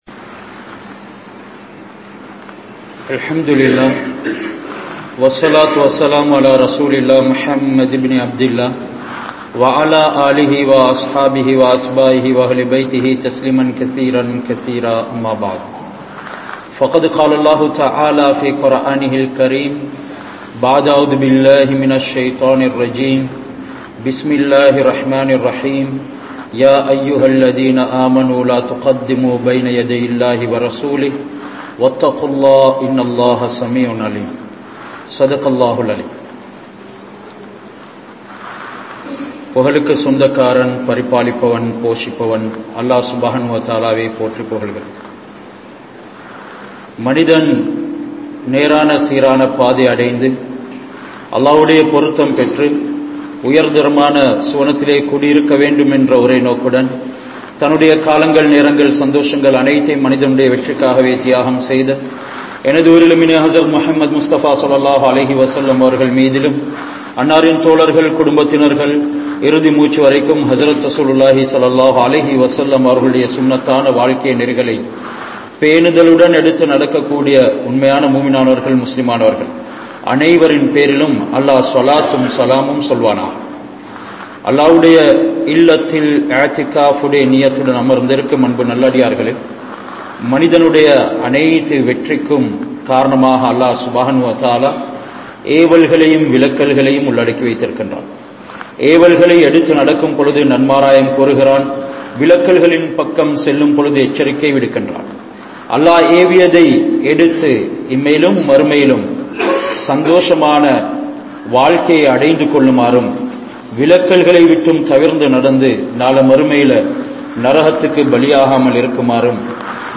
Mattravarhalin Kuraihalai Maraiungal (மற்றவர்களின் குறைகளை மறையுங்கள்) | Audio Bayans | All Ceylon Muslim Youth Community | Addalaichenai
Ilham Babilla Jumua Masjidh